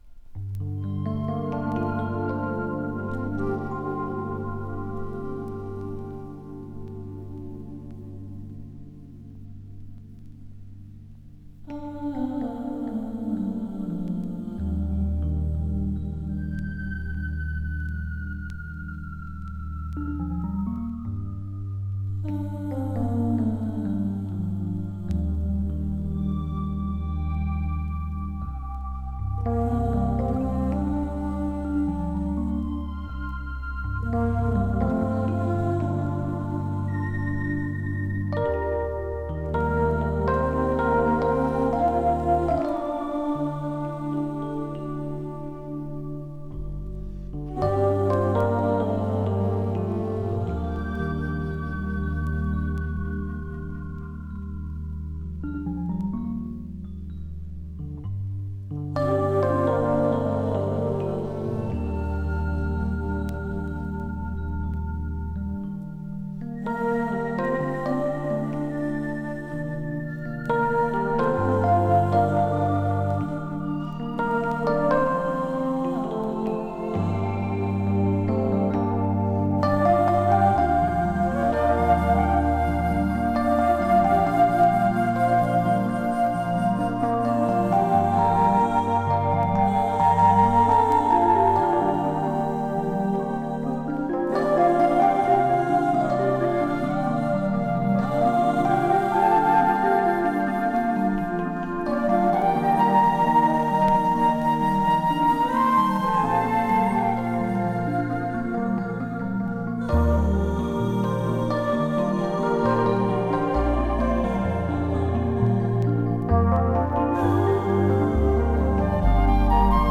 Indie Fusion from L.A.!
【FUSION】【NEW AGE】